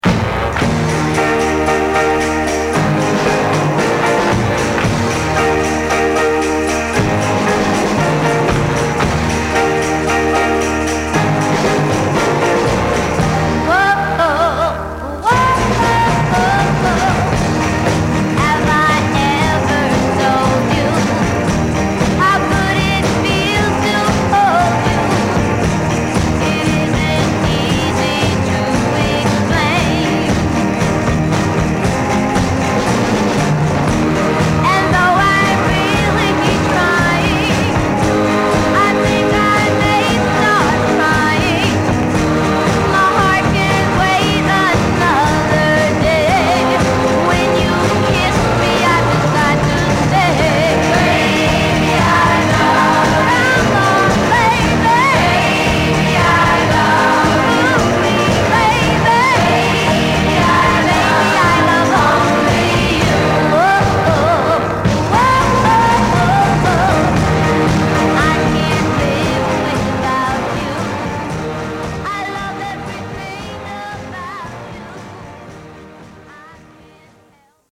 60年代に最も成功したガールズ・コーラスグループの一つ
明るく弾けるバックトラックにラブリーなリリックを乗せたキュートなポップソング！